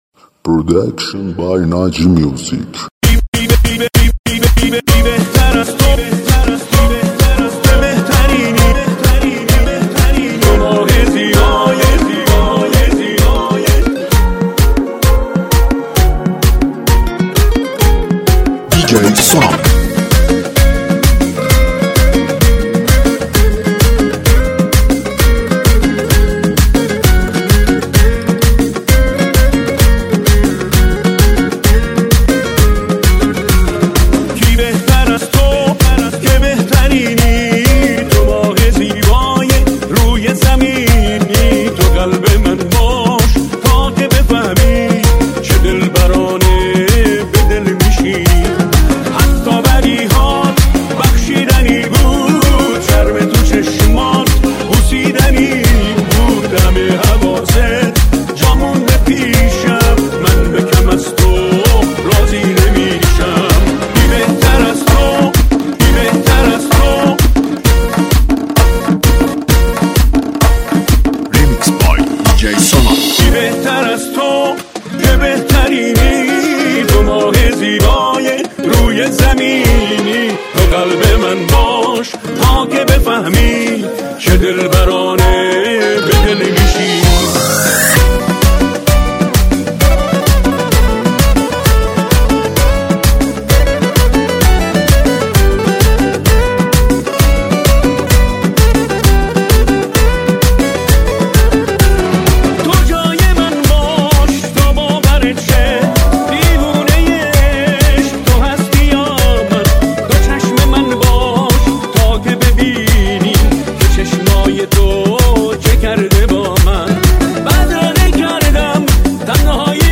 آهنگ شاد تریبال
آهنگ شاد تریبال مخصوص پارتی و رقص